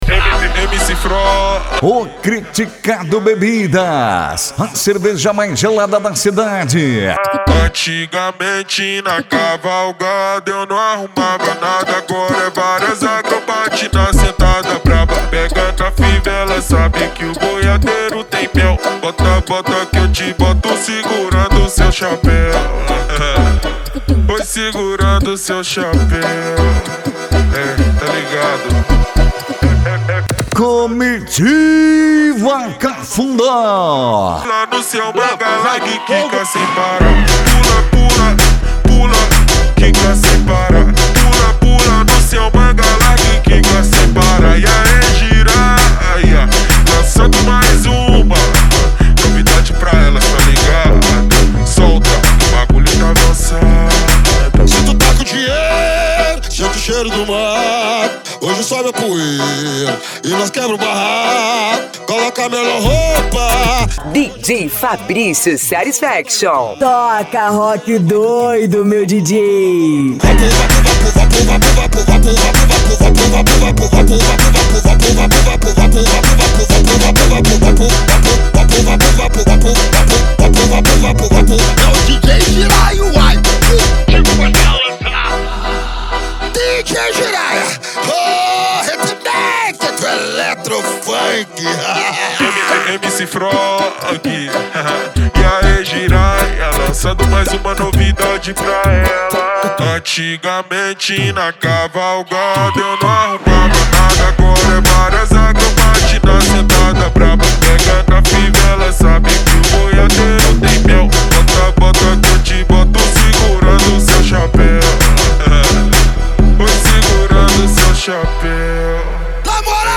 Country Music
Funk
Funk Nejo
SERTANEJO